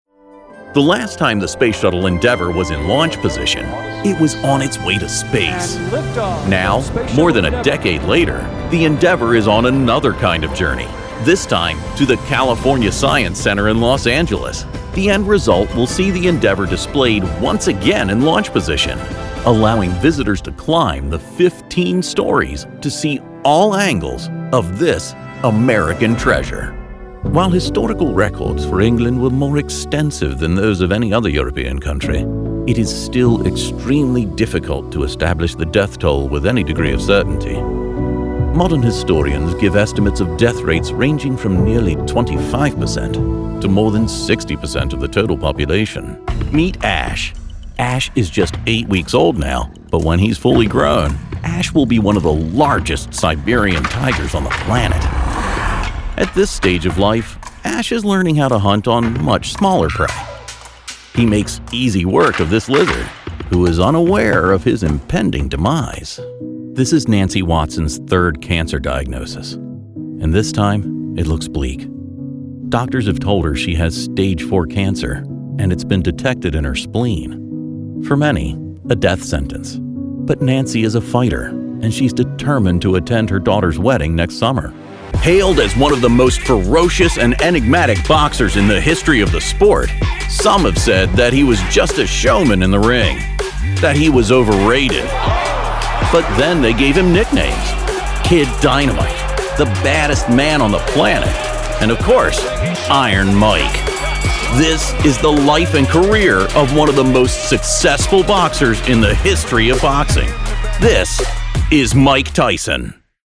Male
Adult (30-50), Older Sound (50+)
Documentary Narration
Words that describe my voice are dynamic, articulate, versatile.
0712Documentary_Demo.mp3